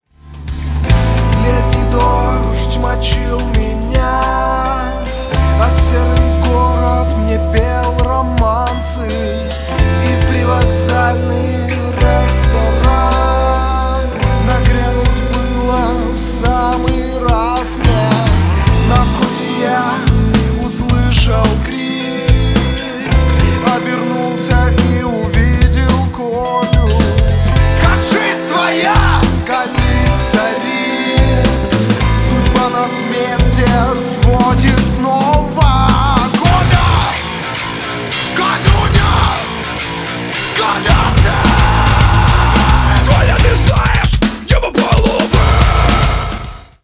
• Качество: 320, Stereo
мужской вокал
hardcore
нарастающие
Alternative Metal